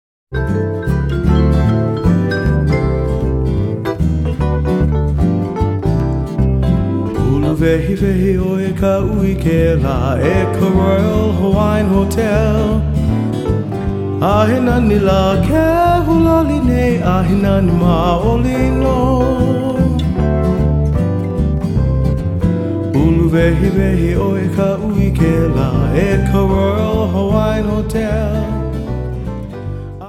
軽快なアップテンポの曲ばかり。